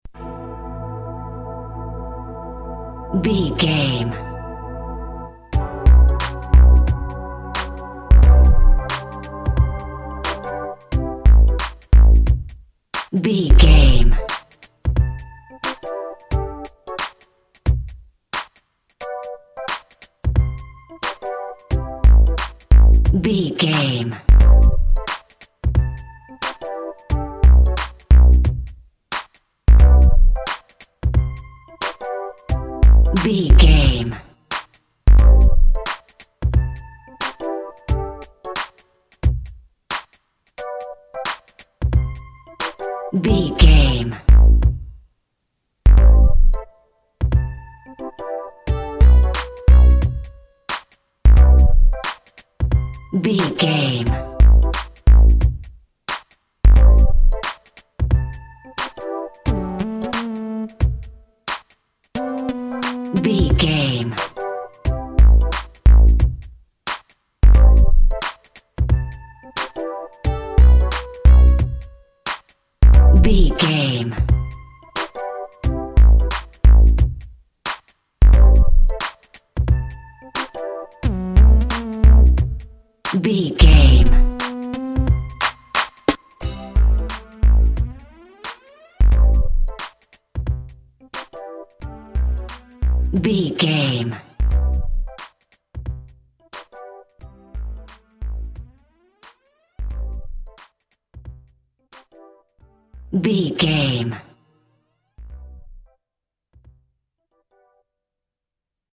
Aeolian/Minor
synth lead
synth bass
hip hop synths
electronics